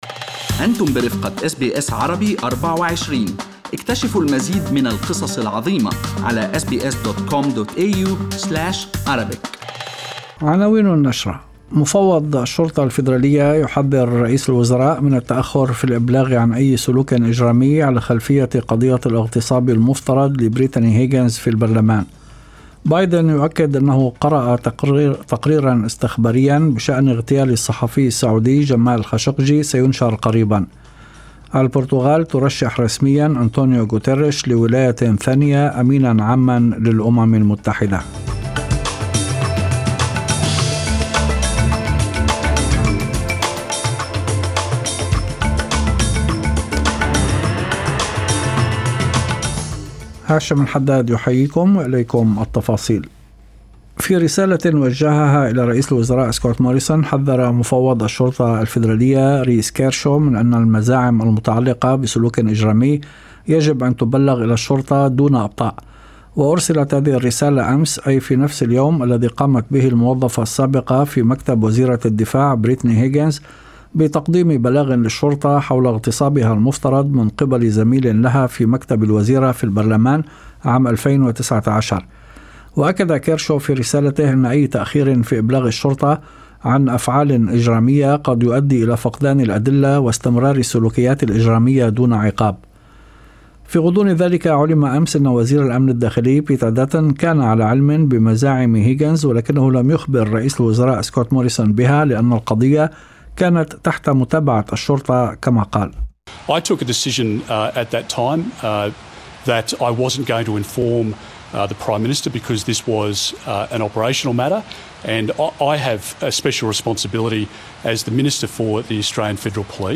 نشرة أخبار المساء 25/02/2021